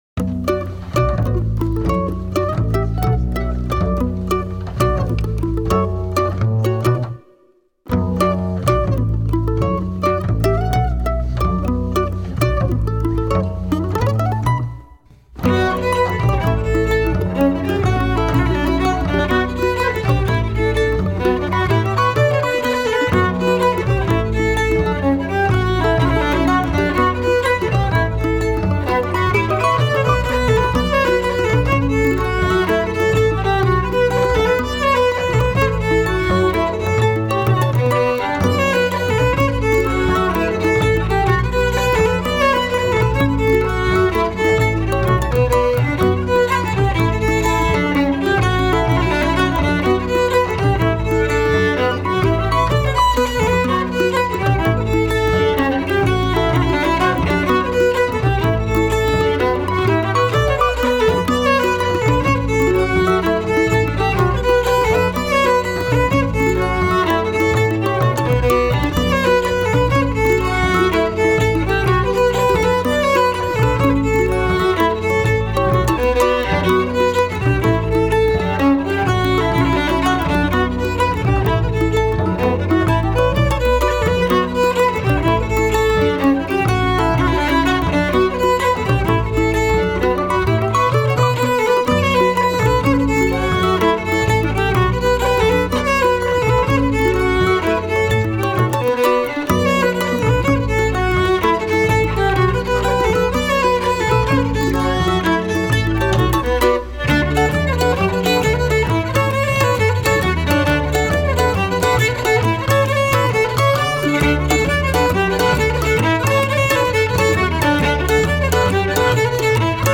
mixer ou cercle circassien ou ...
instruments : violon, mandoline, contrebasse